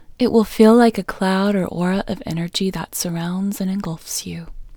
LOCATE OUT English Female 36